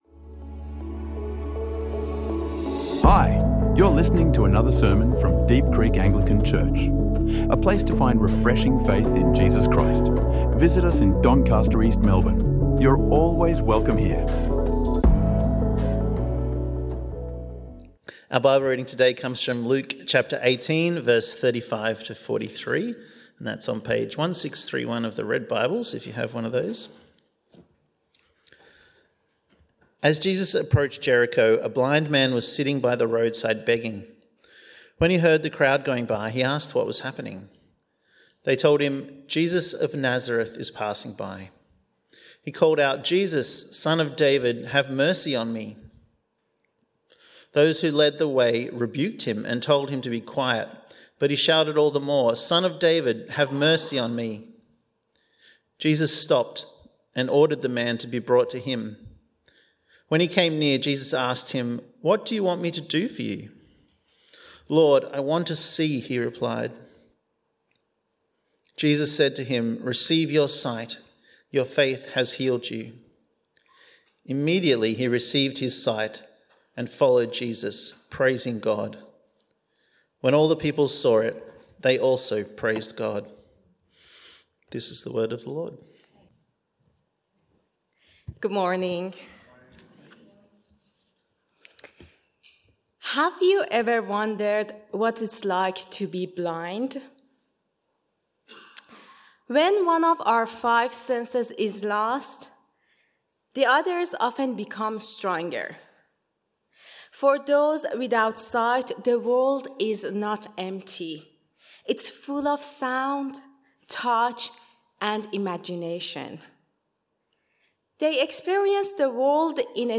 What Do You Want Me to Do For You | Sermons | Deep Creek Anglican Church